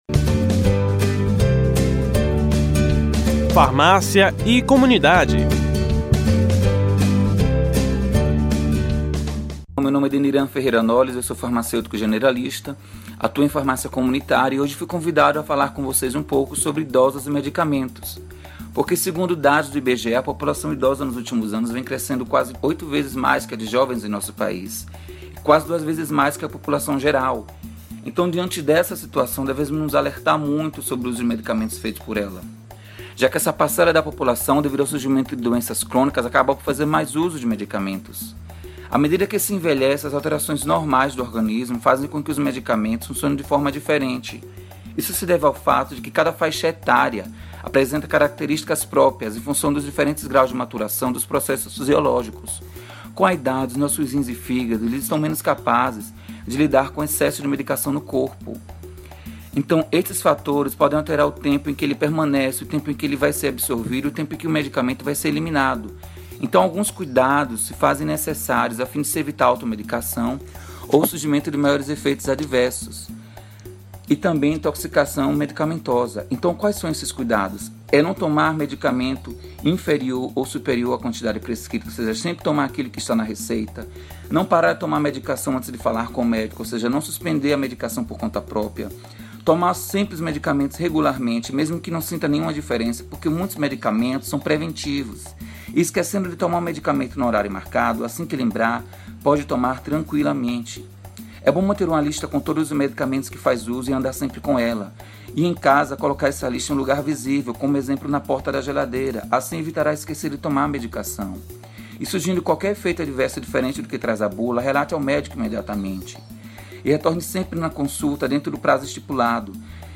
O assunto foi tema do quadro Farmácia e Comunidade, que vai ao ar toda terça-feira, no Programa Saúde no ar, veiculado pelas Rádios Excelsior AM840 e Rádio Saúde no ar. Ouça o comentário: